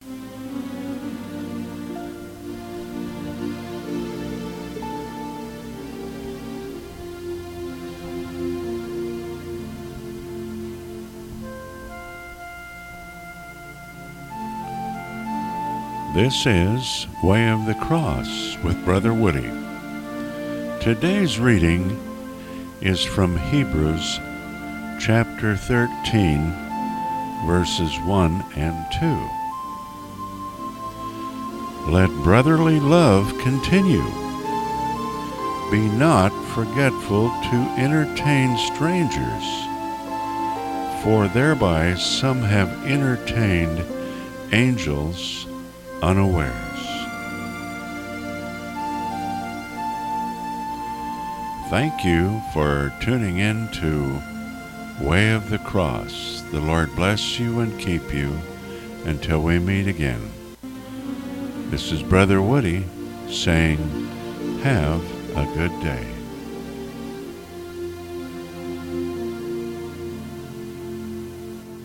Bible readings